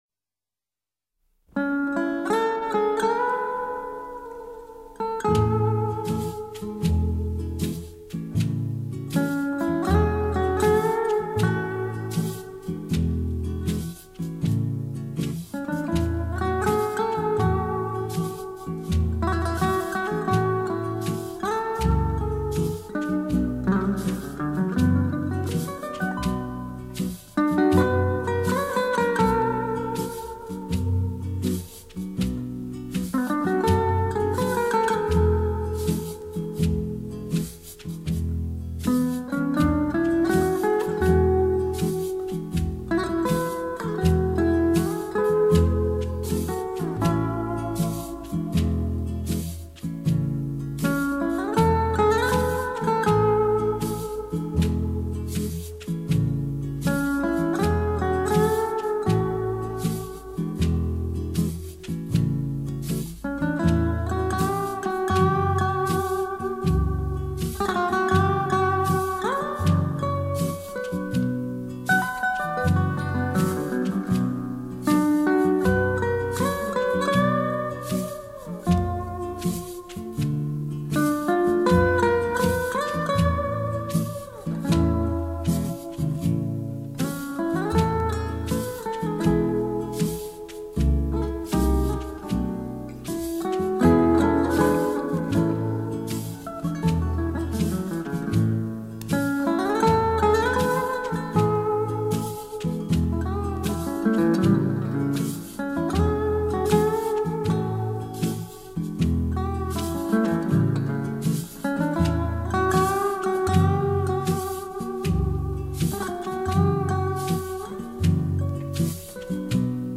流派：Latin